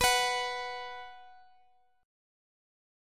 Listen to B5 strummed